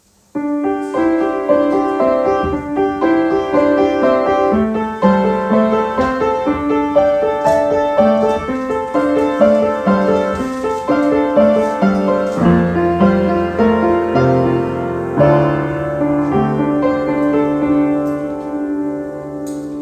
Flower+Girl+Piano+Part+2+m4a.m4a